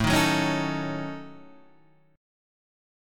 A 6th Flat 5th